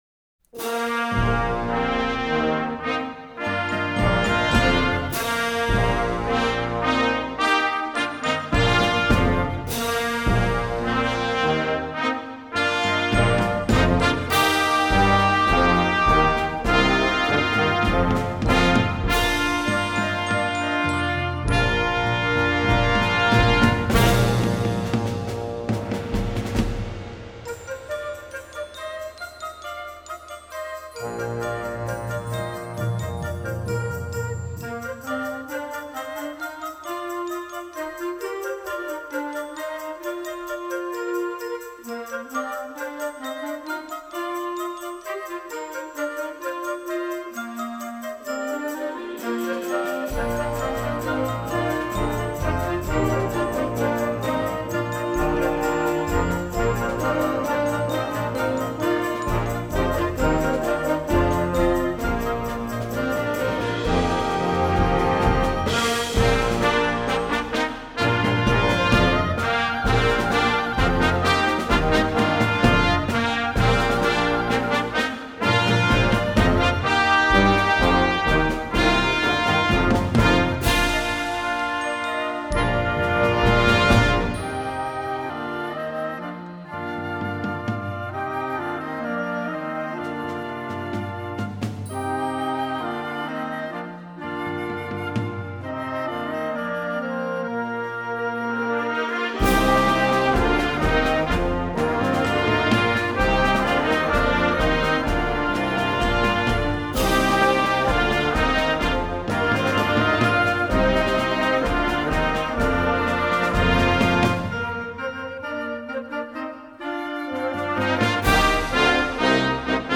Symphonic Band